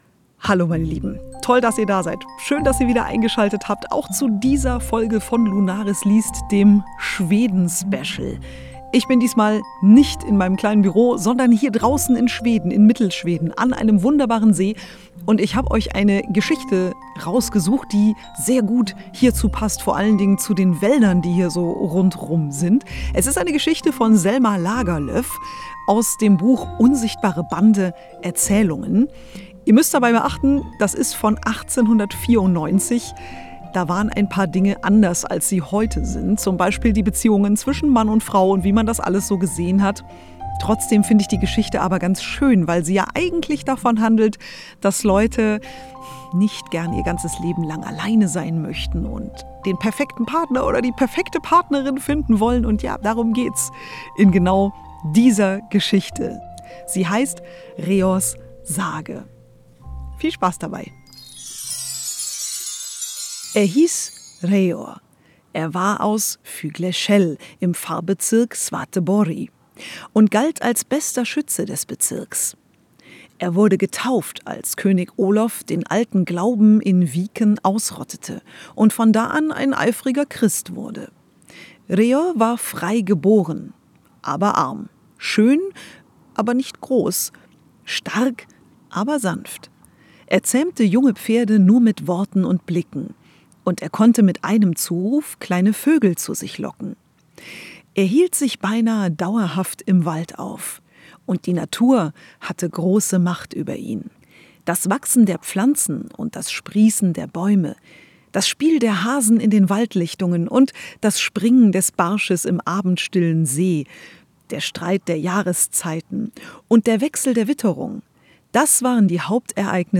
Das erfahrt Ihr in Reors Sage von Selma Lagerlöf - dem zweiten Teil vom Schwedenspecial - eingelesen an einem Fluss in Mittelschweden.